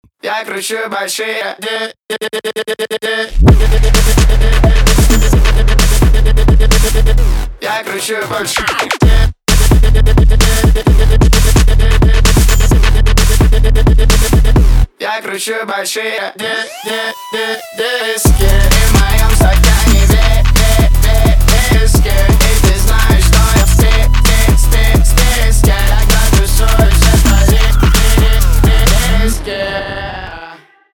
русский рэп
битовые , басы , качающие